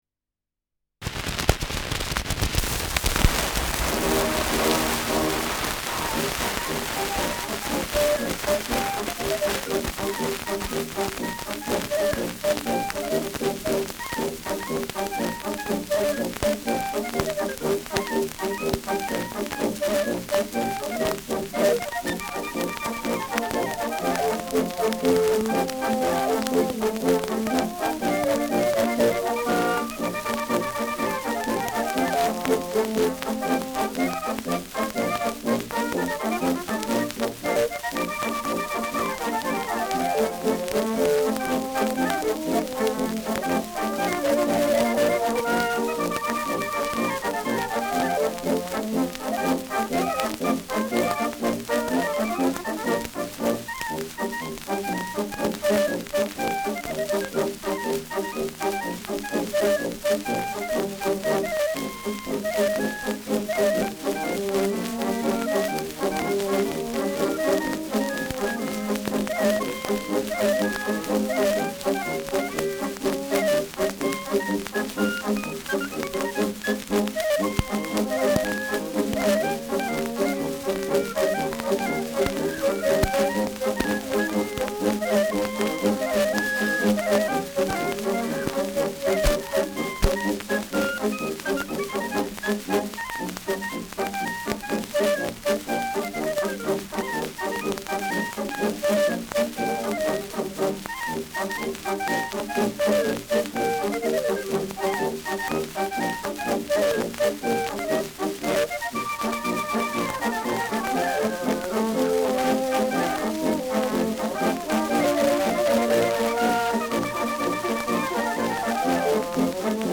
Schellackplatte
präsentes Rauschen
Militärmusik des k.b. 14. Infanterie-Regiments, Nürnberg (Interpretation)
[Nürnberg] (Aufnahmeort)